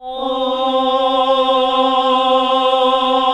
AAH C2 -R.wav